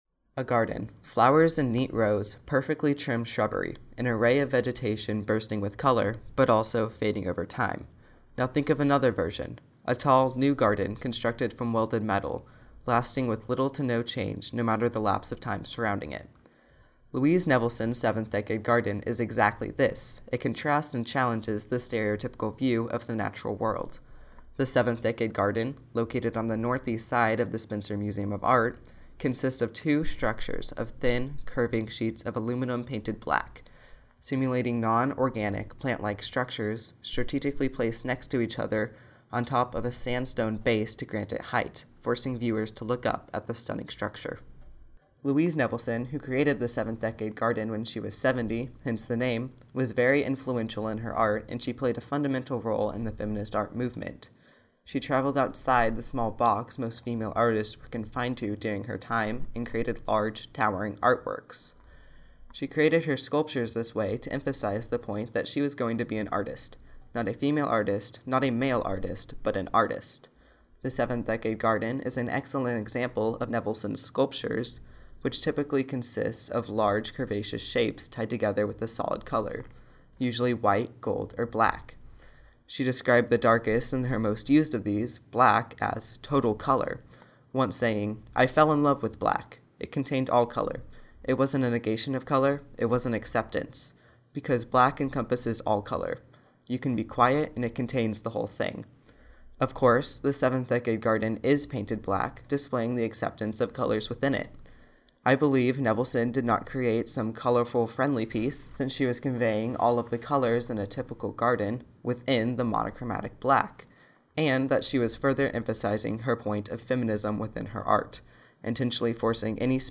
Audio Tour – Bulldog Podcast